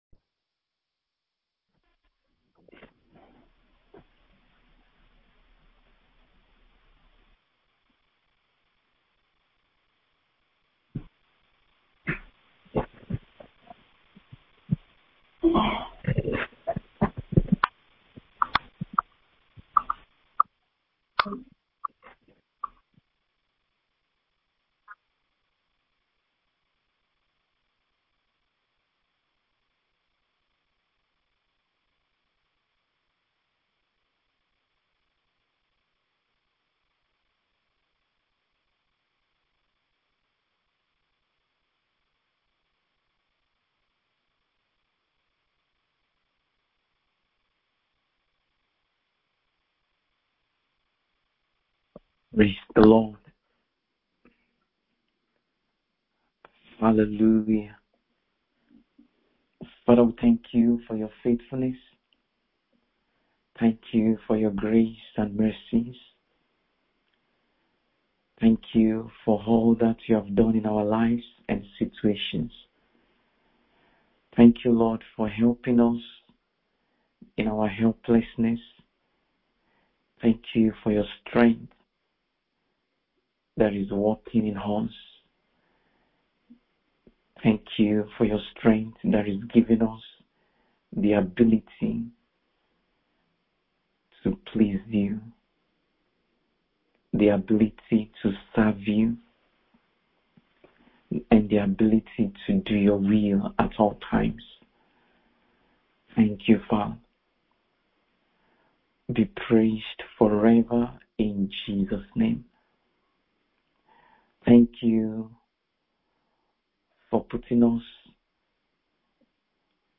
MIDNIGHT PRAYER SESSION : 21 NOVEMBER 2024